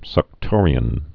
(sŭk-tôrē-ən)